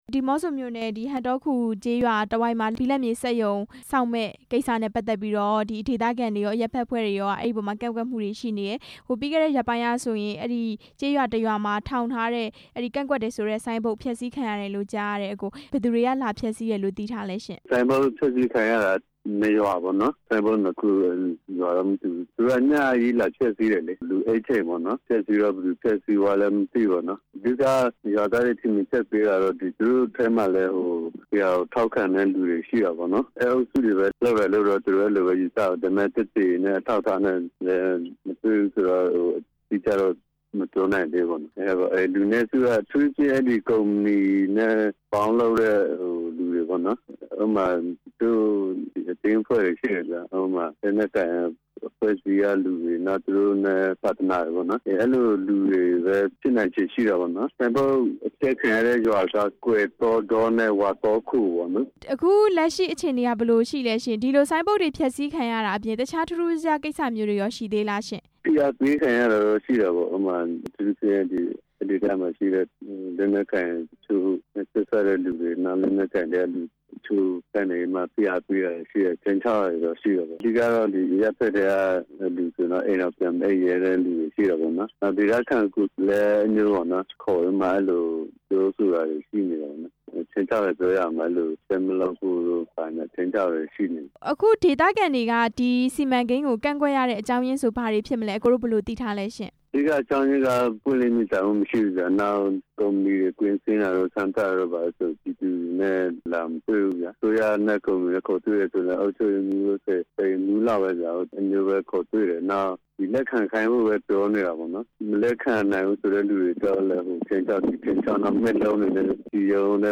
ဘိလပ်မြေစက်ရုံစီမံကိန်း ကန့်ကွက်သူတွေ ခြိမ်းခြောက်ခံရတဲ့အကြောင်း မေးမြန်းချက်